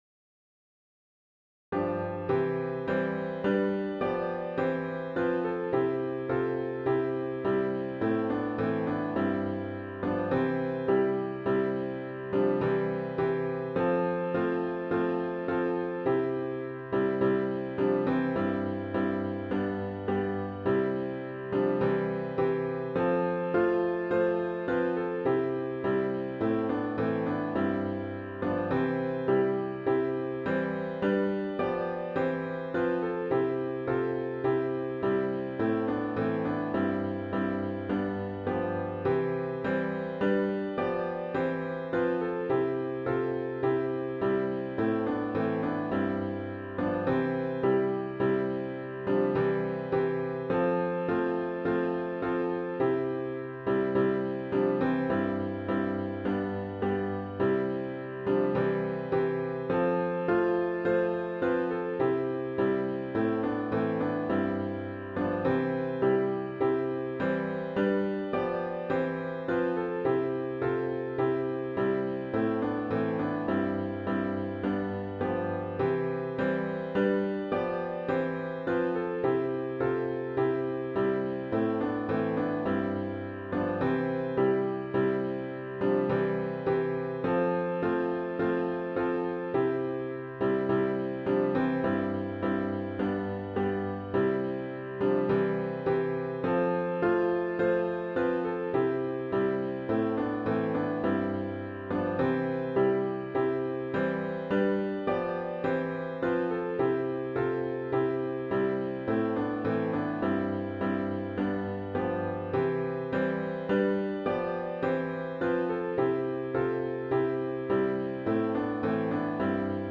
*CLOSING HYMN “He Leadeth Me: O Blessed Thought!”